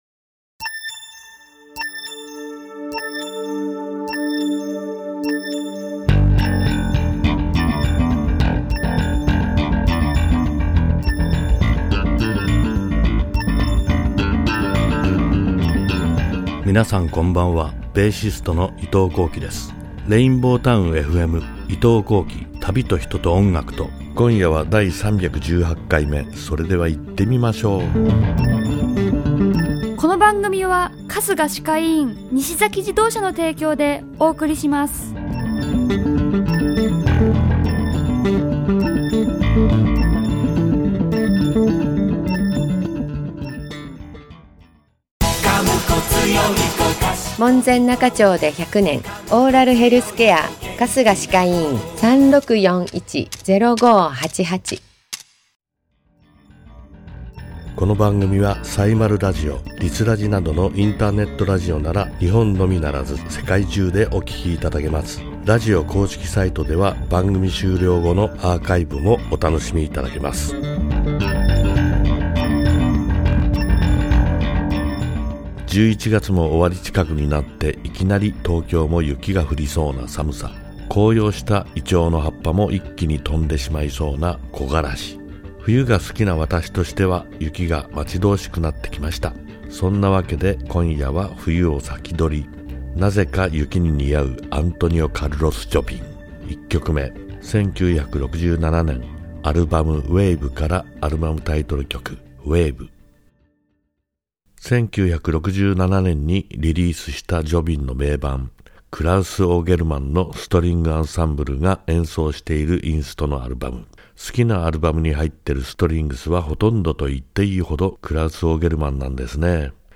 ※アーカイブ・オンデマンドでは、トーク内容のみで楽曲はかけておりません。